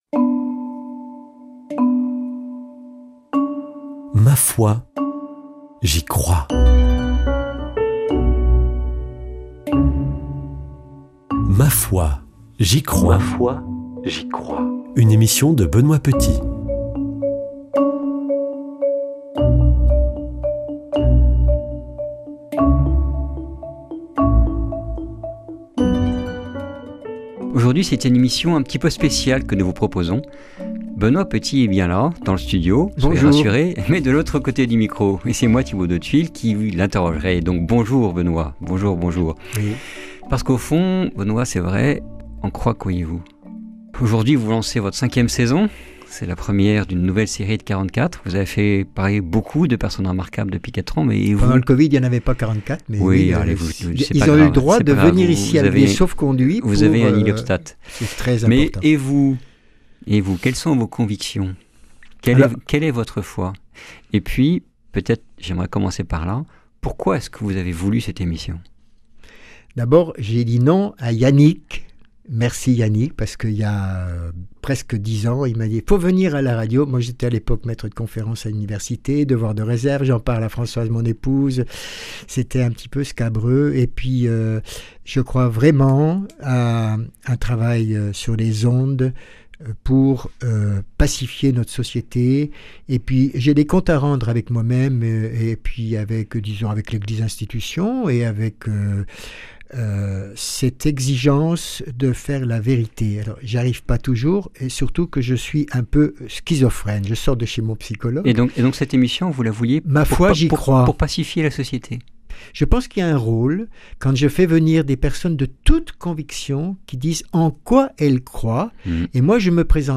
interviewé dans son émission